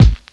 Medicated Kick 14.wav